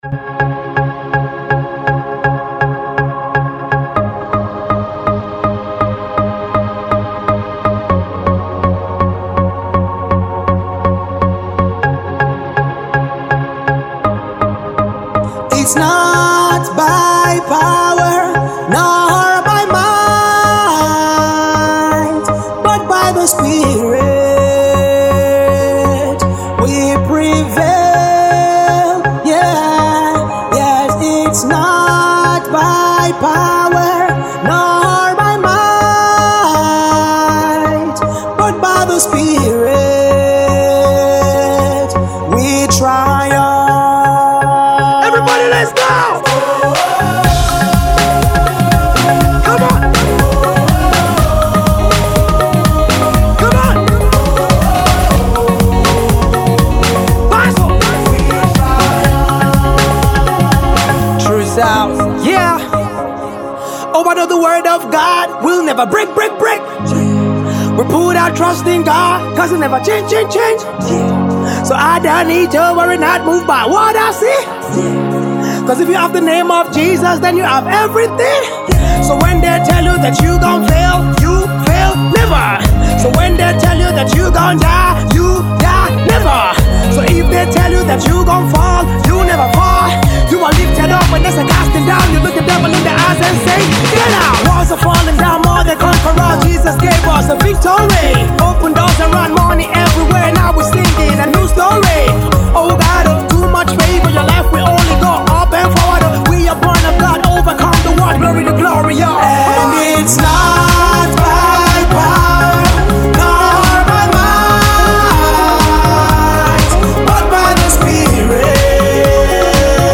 victory song